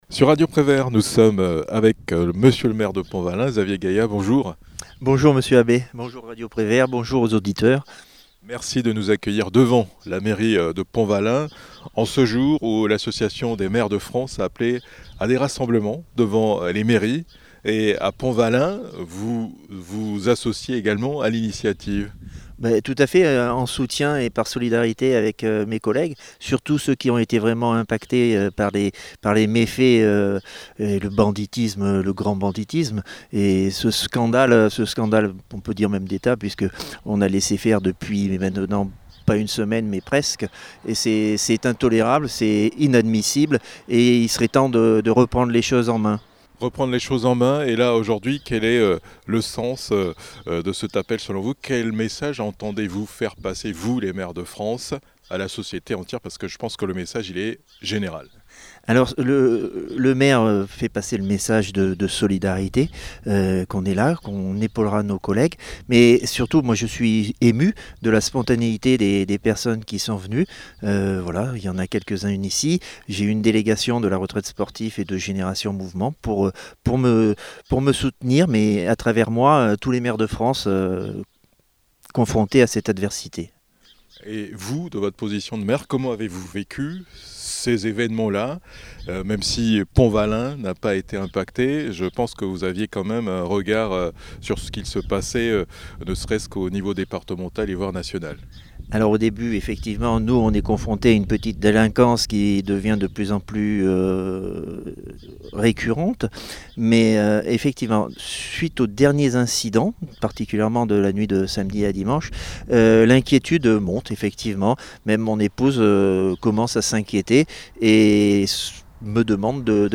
Répondant à l'appel à rassemblement devant les mairies ce 3 juillet 2023, lancé par l'Association des maires de France pour un retour à la paix civile Xavier Gayat, maire de Pontvallain, a accueilli par vagues successives, des vallipontaines et des vallipontains. Lors de notre arrivée, l'élu était avec des membres de la Retraite sportive et de Génération Mouvement se dit solidaire de ses collègues qui ont été agressés lors des émeutes et incite les pouvoirs publics à "reprendre les choses en main".